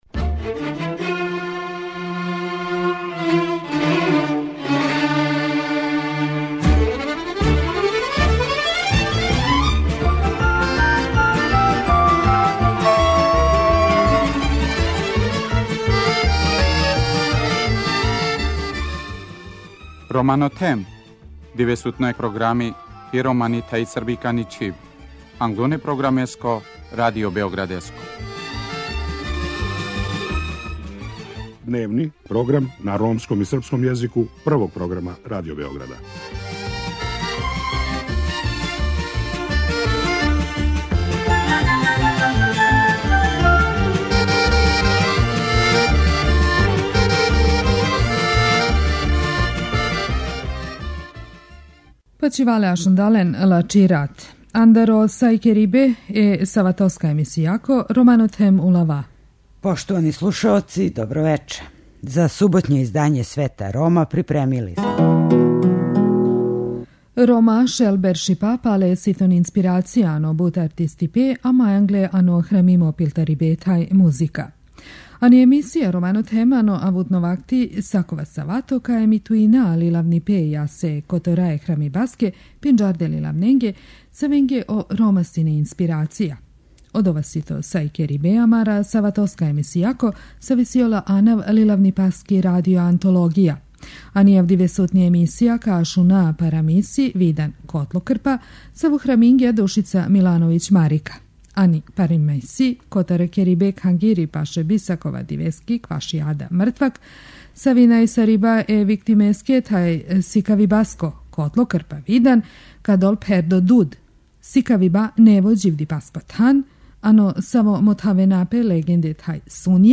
У емисији Свет Рома, сваке суботе емитујемо књижевно дело или одломке разних књижевника којима су Роми били инспирација.